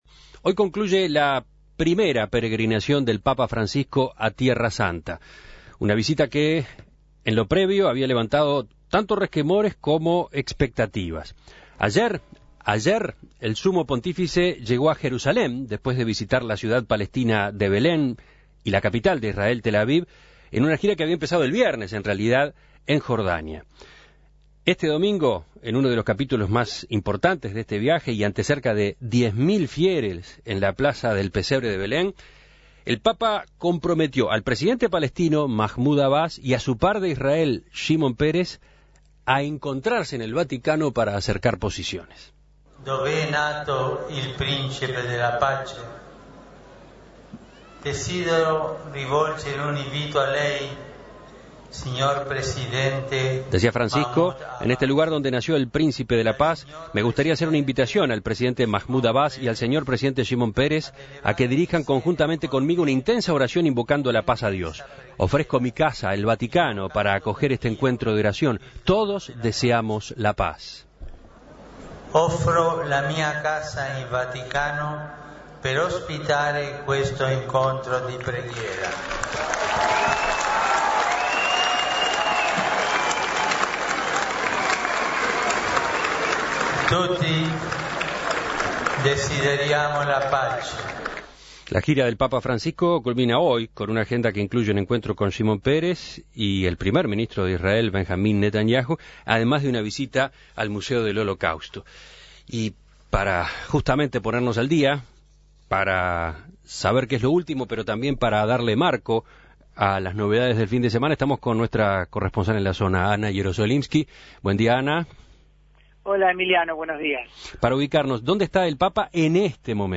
(Audio papa Francisco.)